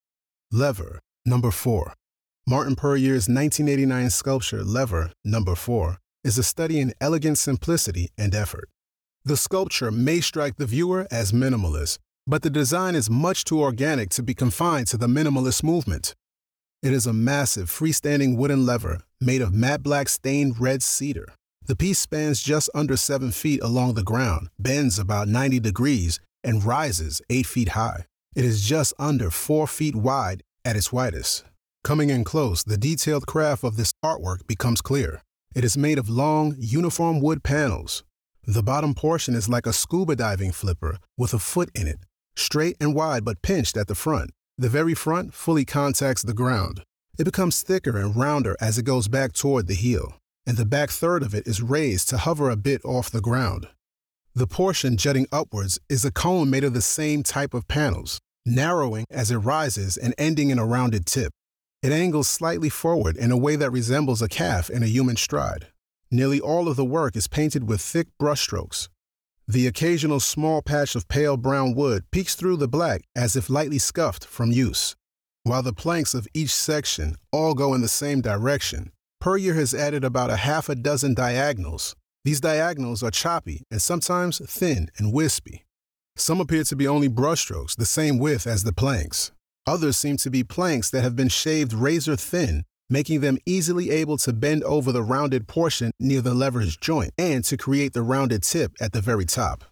Audio Description (01:40)